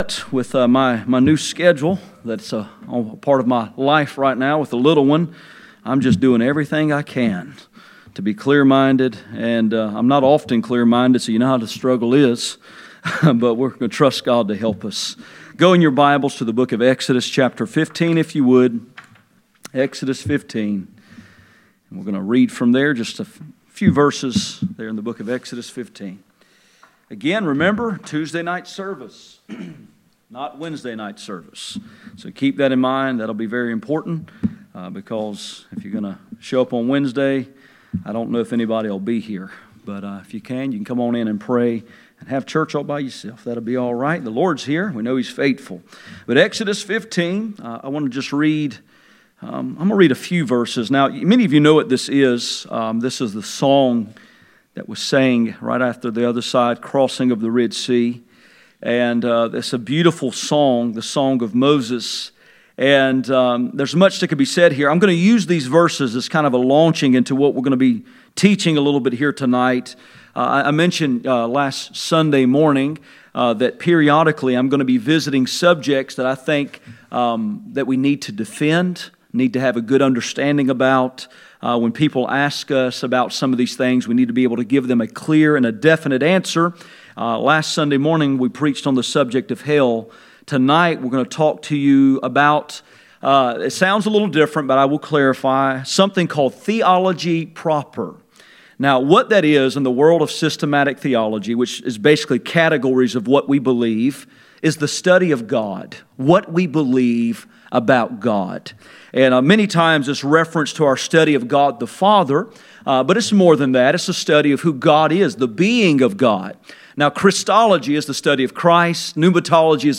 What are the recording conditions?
Exodus 15:7-11 Service Type: Sunday Evening %todo_render% « Higher Ground The preeminence of Christ